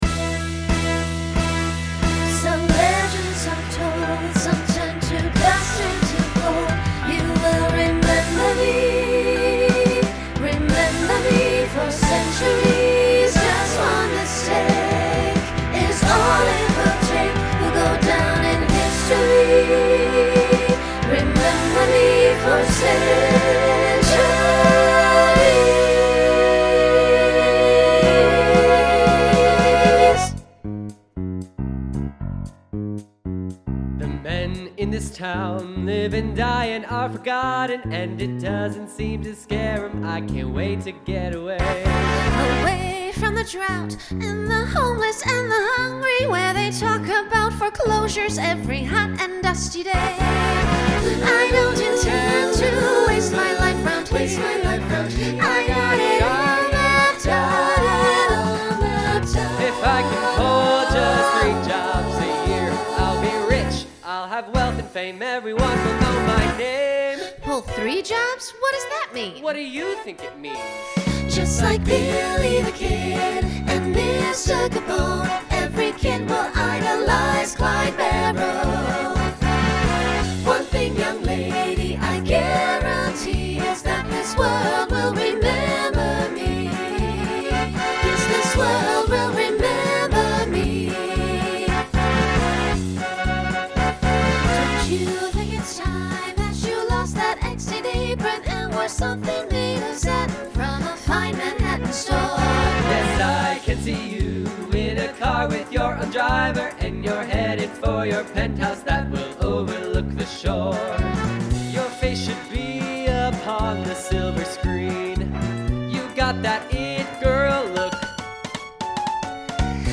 Treble/Bass clef duet throughout.
Rock
Voicing SATB